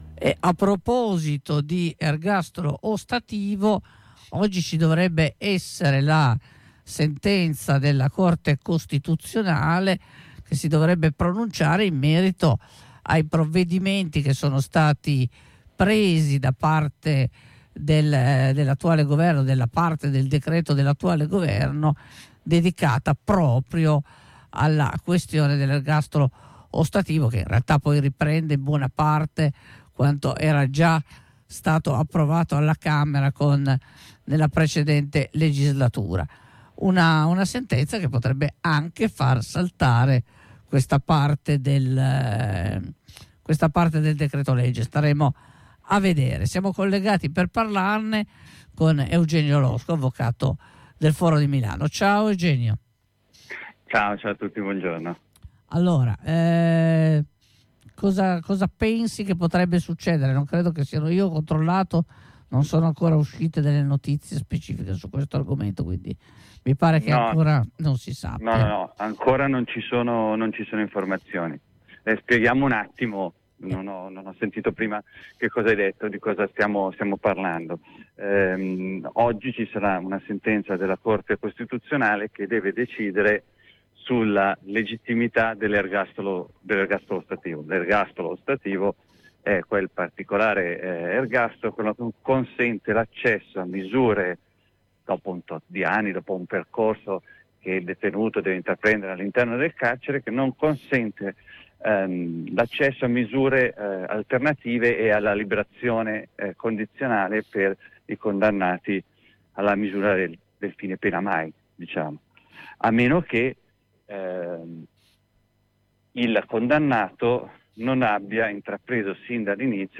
Ascolta la diretta: